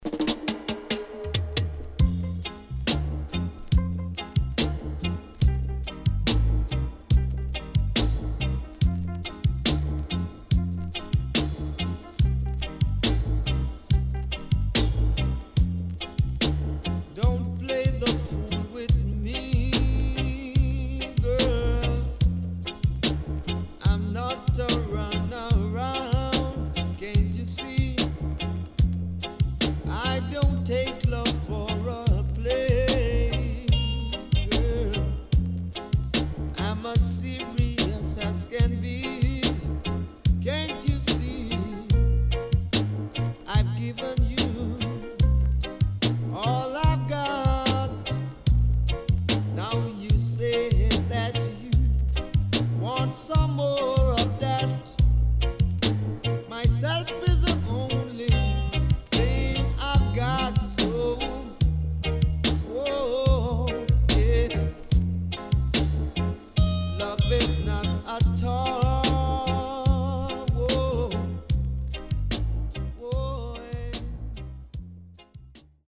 HOME > LP [DANCEHALL]  >  SWEET REGGAE  >  定番70’s
SIDE B:少しチリノイズ入りますが良好です。